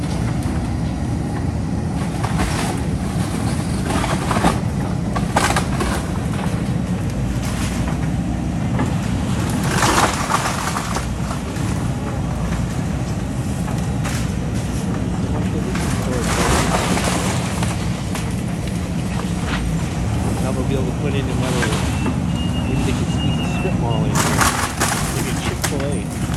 The historic home located next to the Citgo gas station on East Diamond is being demolished today.